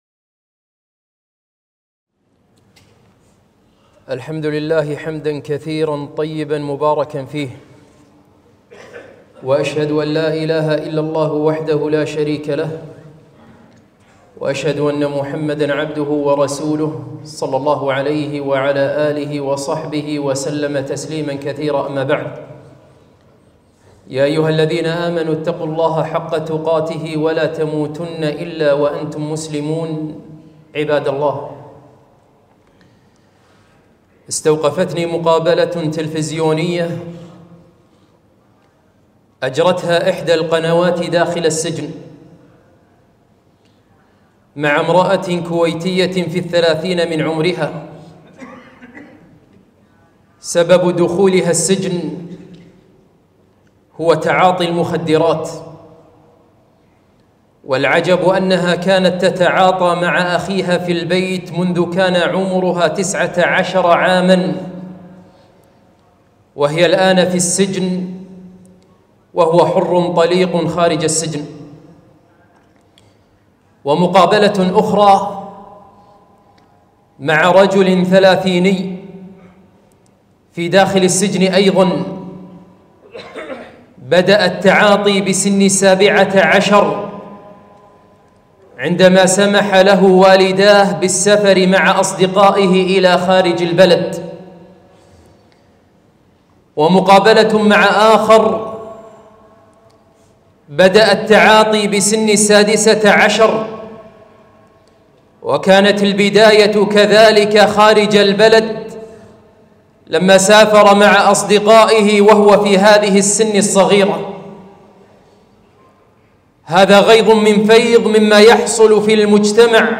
خطبة - لماذا أدمنوا المخدرات؟